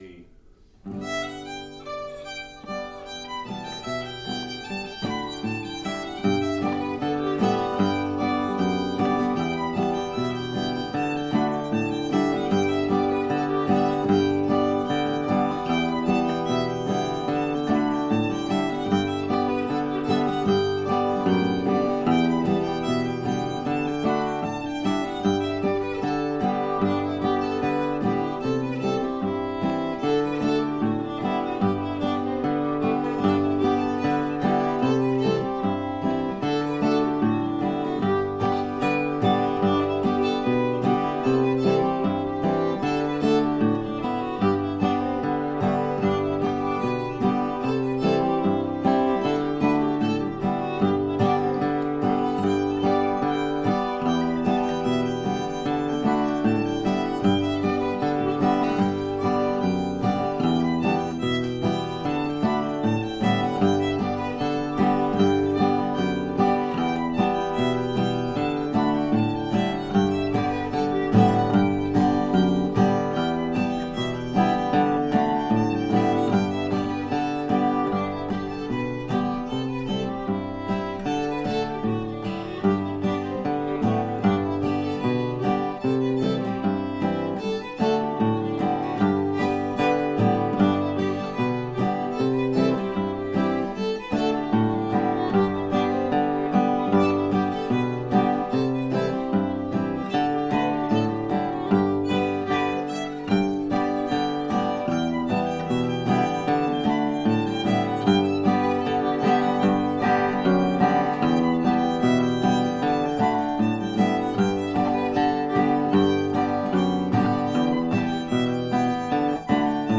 Here's a sample of every tune that has been recorded at the Pegram Jam
Most of the recordings should be clear enough to be useful for learning melodies and for practice accompaniment.